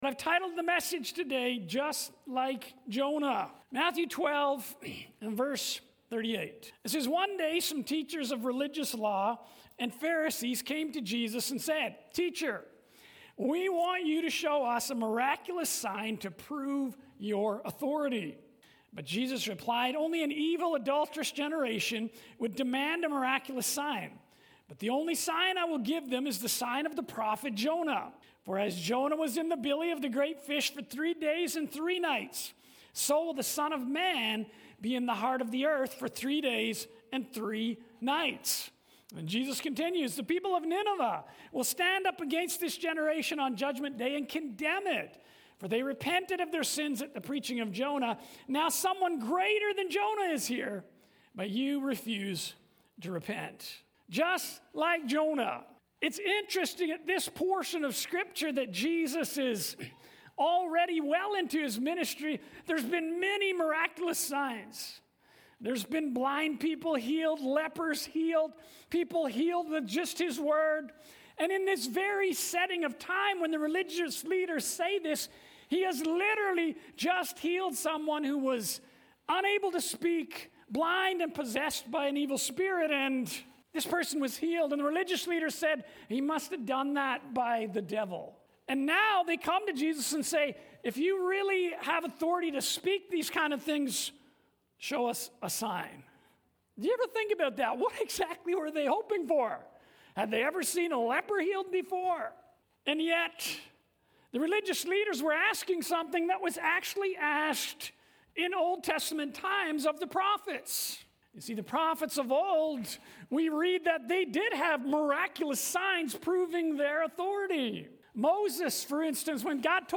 Sermons | Abundant Life Worship Centre